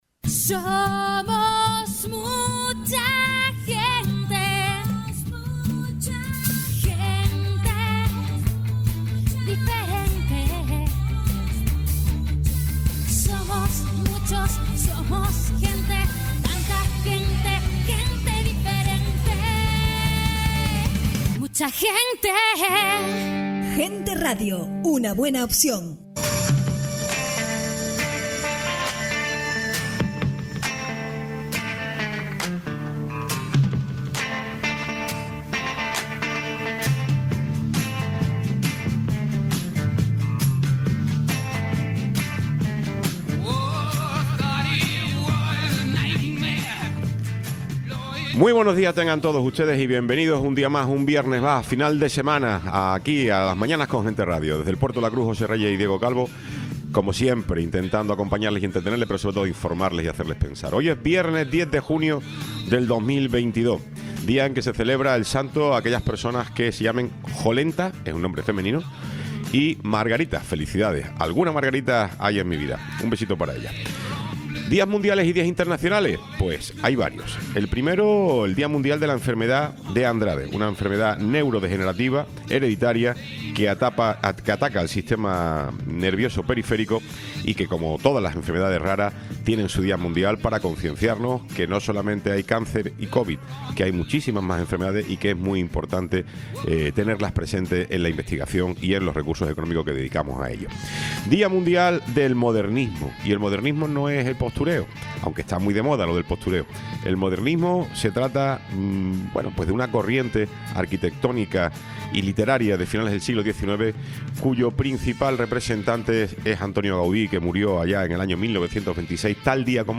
Sección Gente en Igualdad y Diversidad Tiempo de entrevista
Tertulia